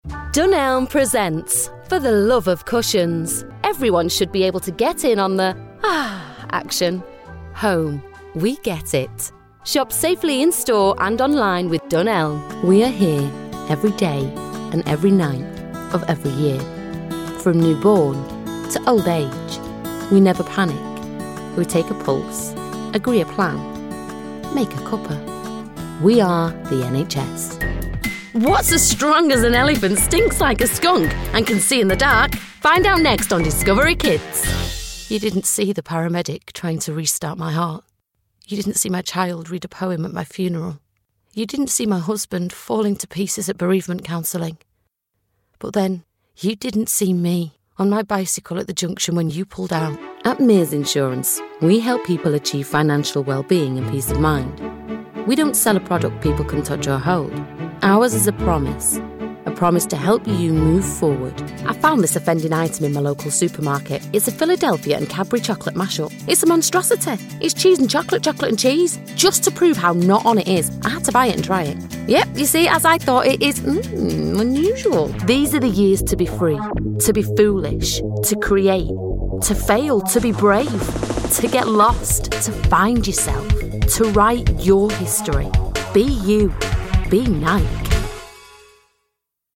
British Female Voice over with 16 years experience and a Professional studio.
Warm, engaging and conversational
Sprechprobe: Werbung (Muttersprache):